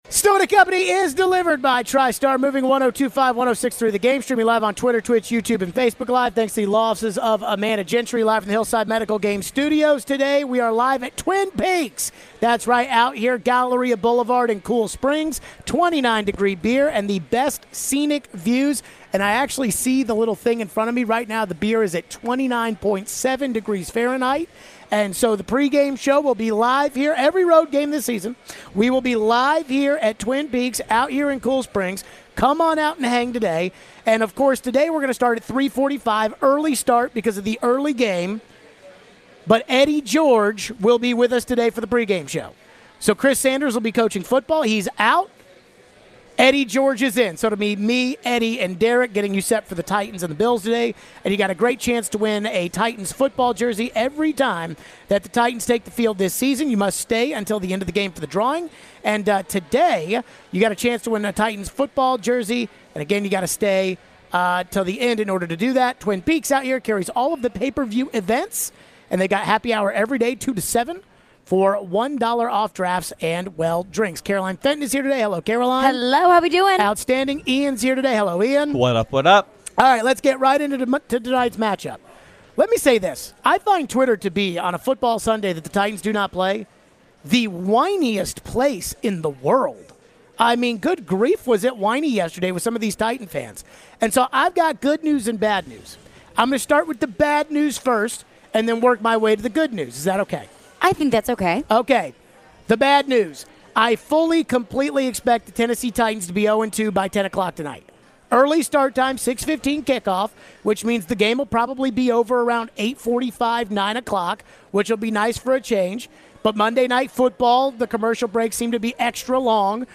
Could Buffalo be without a WR tonight? we take your phones.